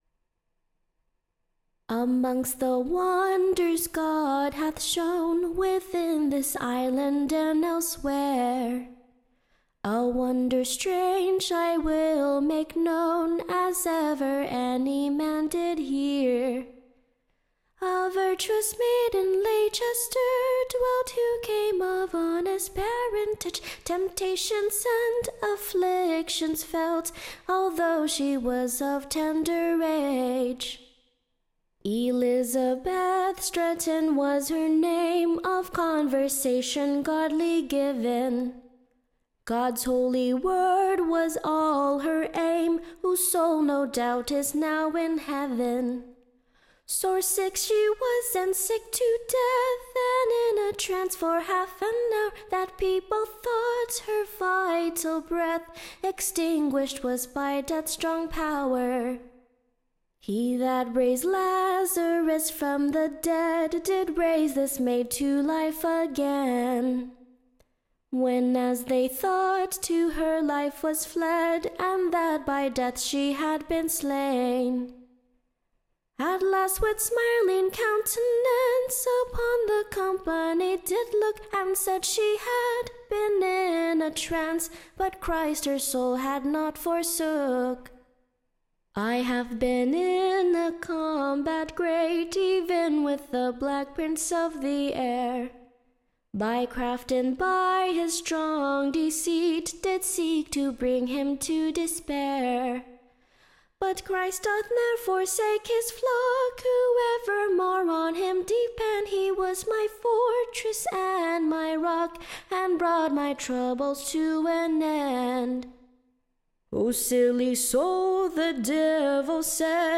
Ballad
To the Tune of, In Summer-time, &c.